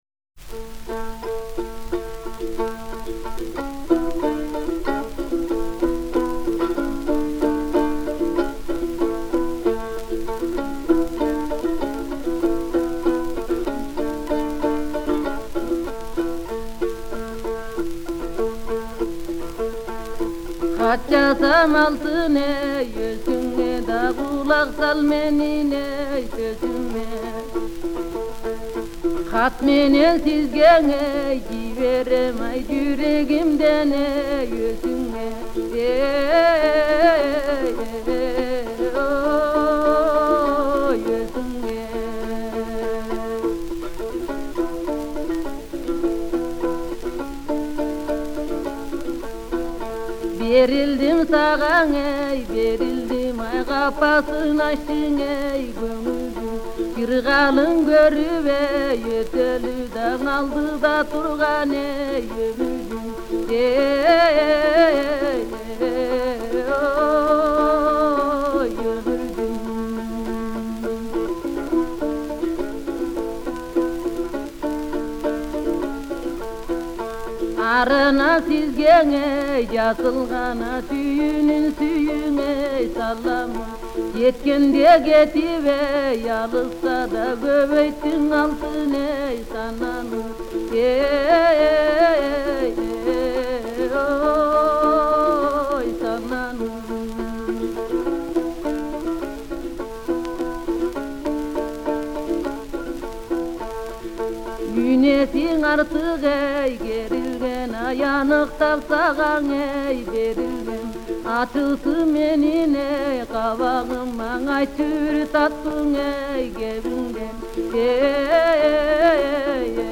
Genre: Early Ethnic